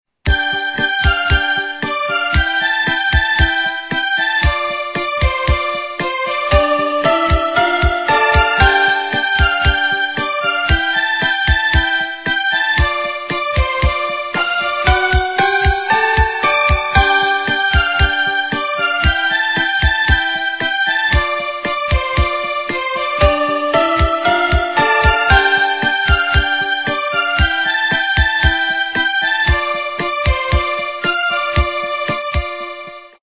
- русская эстрада
качество понижено и присутствуют гудки
полифоническую мелодию